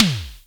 Index of /90_sSampleCDs/Roland L-CDX-01/DRM_Analog Drums/TOM_Analog Toms
TOM DOLBY 07.wav